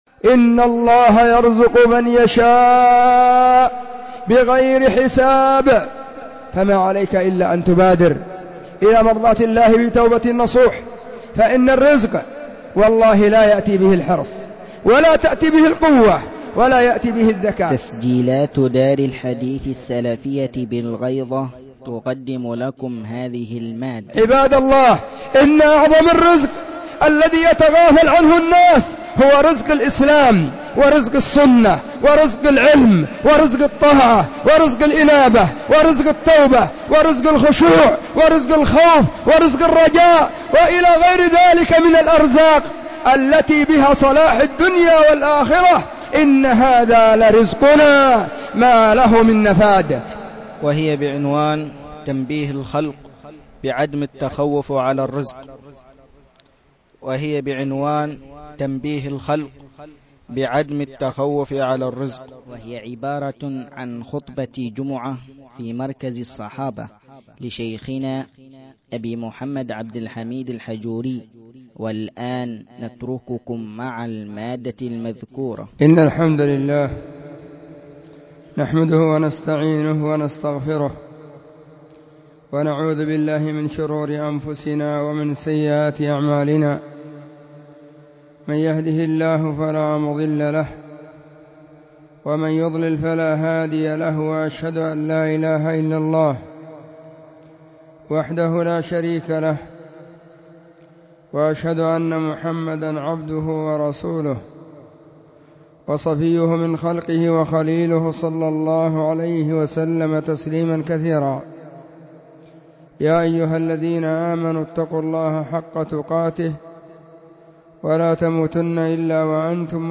خطبة الجمعة بعنوان: تنبيه الخلق بعدم التخوف على الرزق بتاريخ الجمعة الموافق 25/ محرم / 1440 هجرية.
📢 وكانت في مسجد الصحابة بالغيضة، محافظة المهرة – اليمن.
خطبة_الجمعة_تنبيه_الخلق_بعدم_التخوف.mp3